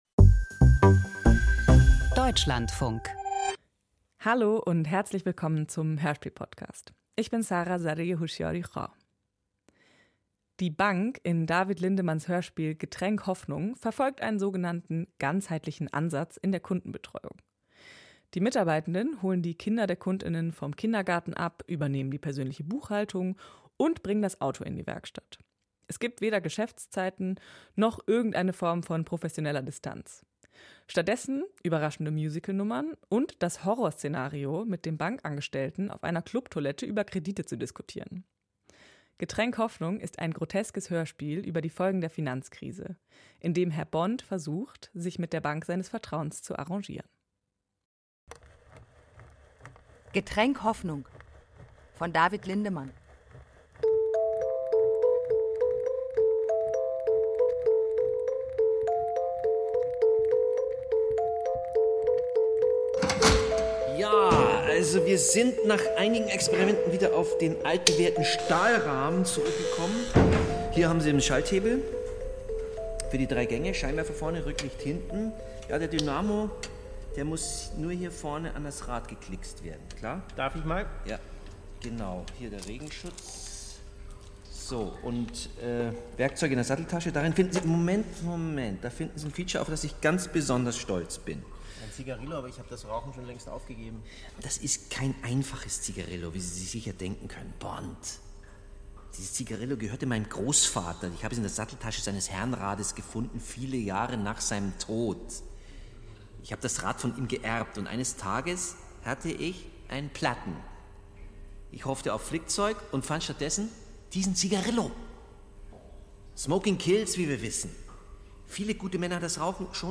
Finanzberaterin Merkel nimmt sich Zeit für ihre Kunden. Ein Hörspiel über das Prinzip „Vertrauen“ im Bankgeschäft.